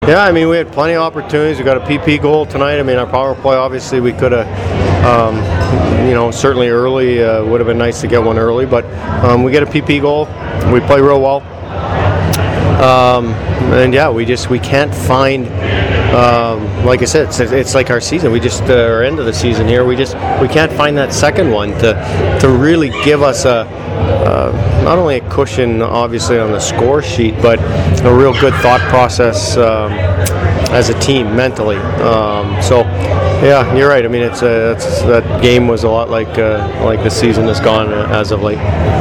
I’m not sure if the audio on my recorder came off poorly or if I’m just bad of hearing from the concert while editing the audio.